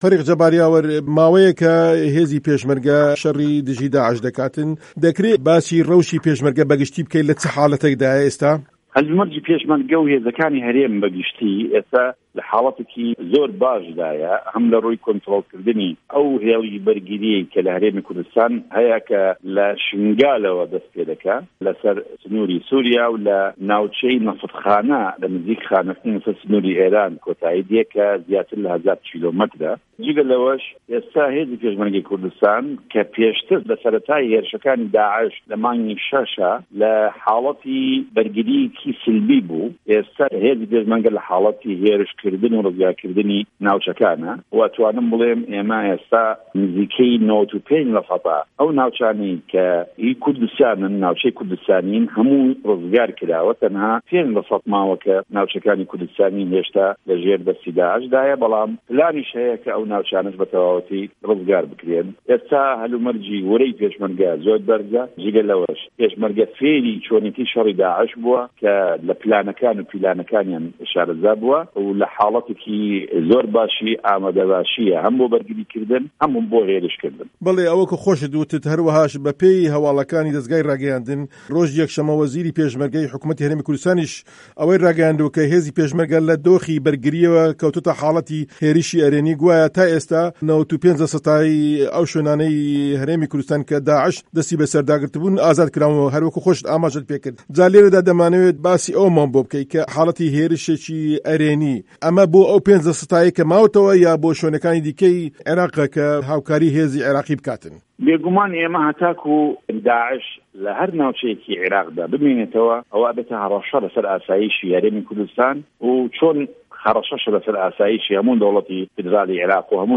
وتووێژی جه‌بار یاوه‌ر